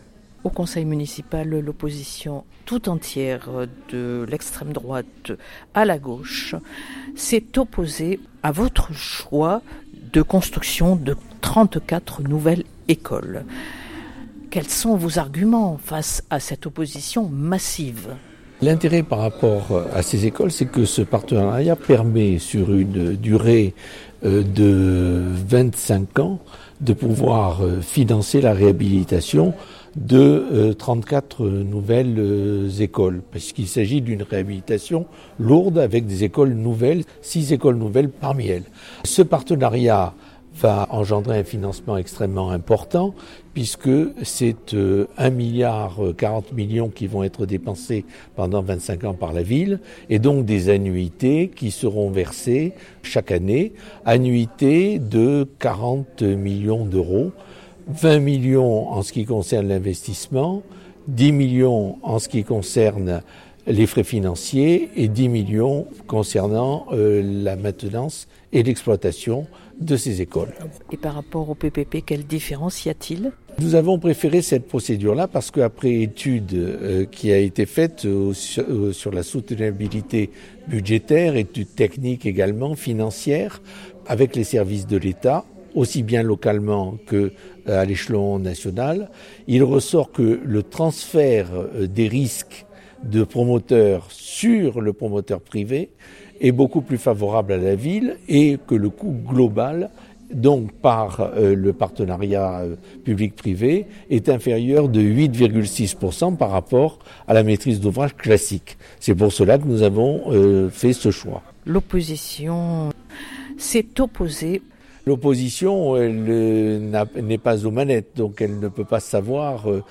Les entretiens